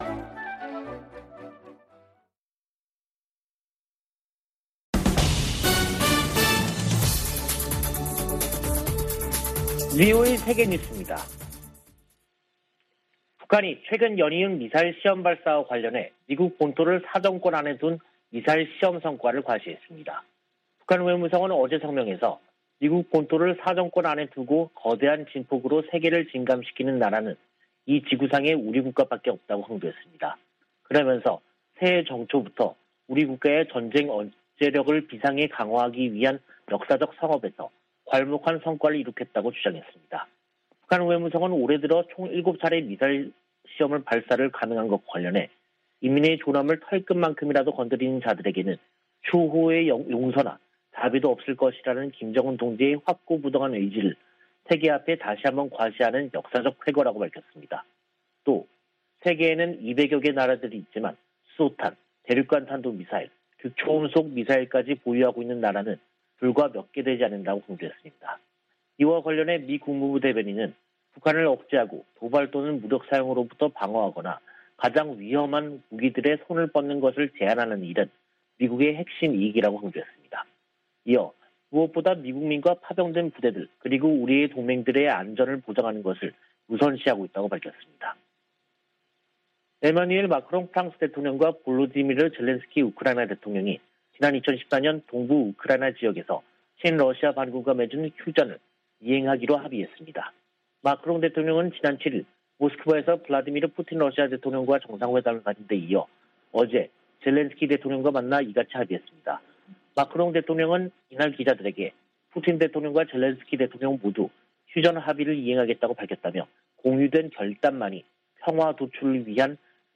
VOA 한국어 간판 뉴스 프로그램 '뉴스 투데이', 2022년 2월 9일 3부 방송입니다. 미 국무부는 대북 인도주의 지원을 위한 '제재 면제' 체제가 가동 중이라며, 제재가 민생을 어렵게 한다는 중국 주장을 반박했습니다. 유엔이 지원 품목을 제재 면제로 지정해도 북한의 호응을 얻지 못한 채 속속 기간 만료되고 있는 것으로 나타났습니다. 북한 대륙간탄도미사일(ICBM) 기지 완공이 처음 확인됐다고 미 전략국제문제연구소(CSIS)가 밝혔습니다.